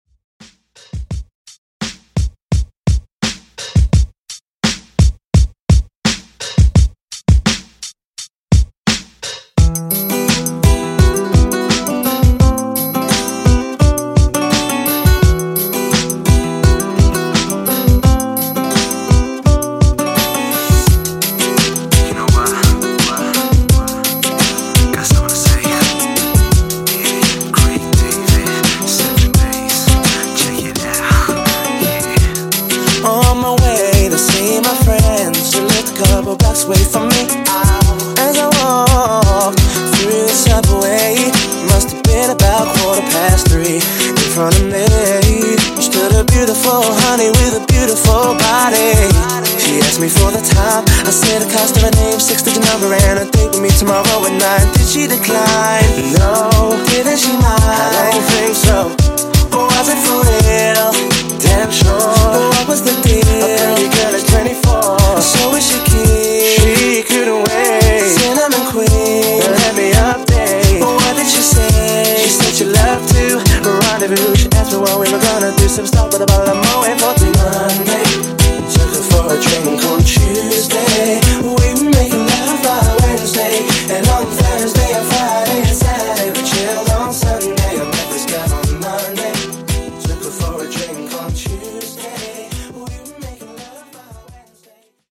RnB Redrum)Date Added